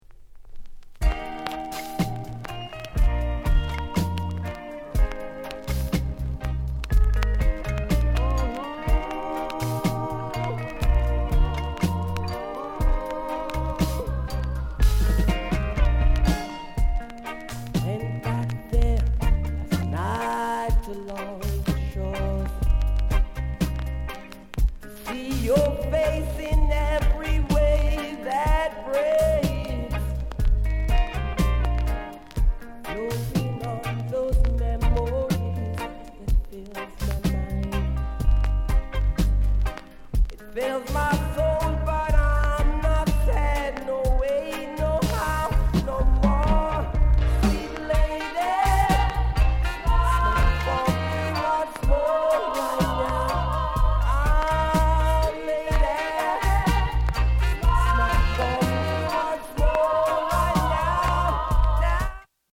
��VG�� �٤��ʽ�����ޤ����ɹ������Ǥ��� ������ NICE SWEET VOCAL